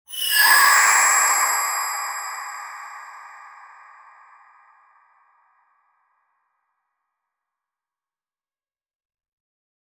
A beautiful SFX sound for a character entering a magic portal
a-beautiful-sfx-sound-for-qrcljmbb.wav